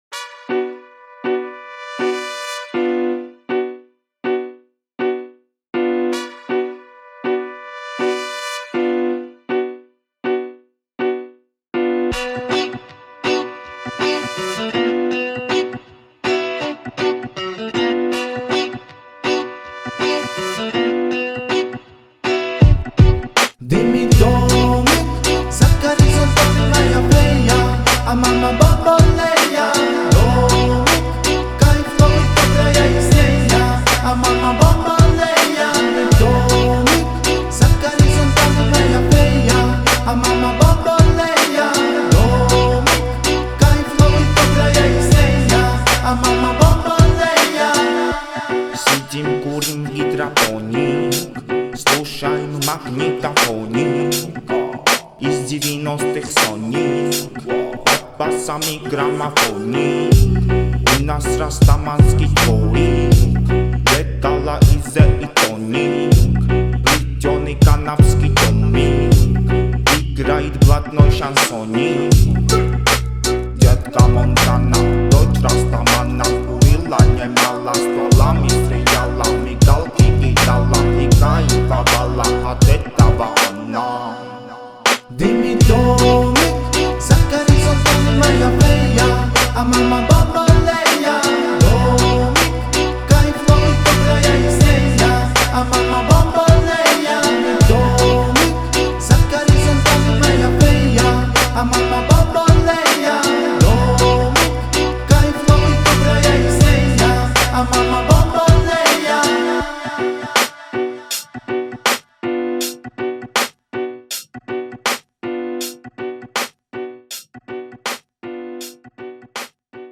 который сочетает в себе элементы хип-хопа и поп-музыки.